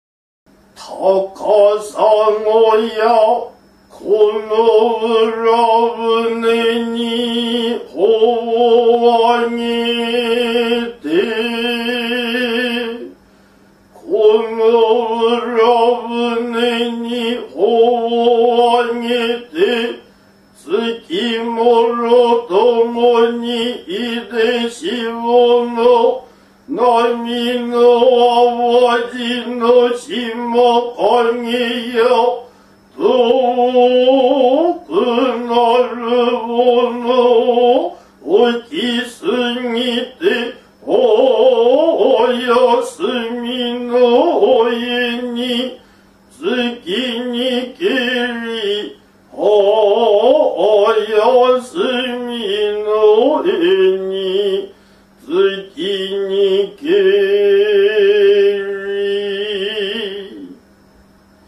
★　　能楽 「 高砂 」